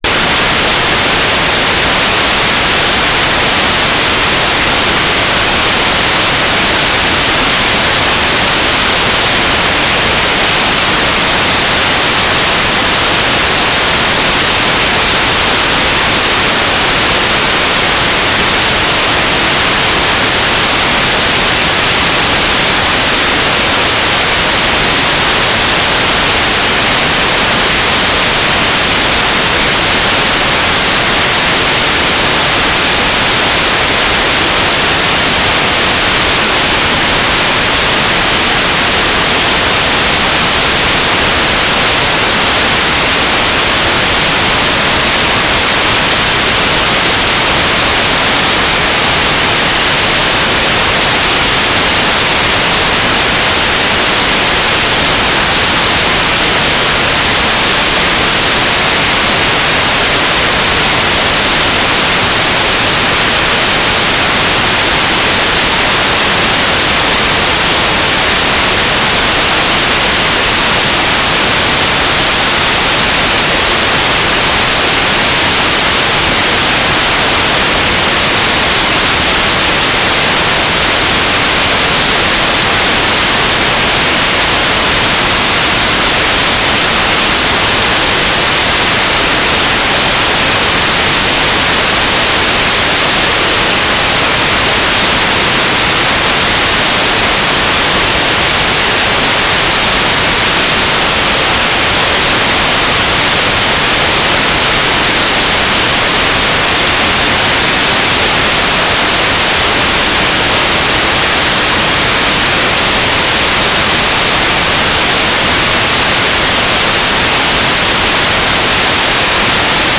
"transmitter_description": "Mode U - Transmitter",
"transmitter_mode": "FM",